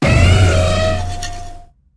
greattusk_ambient.ogg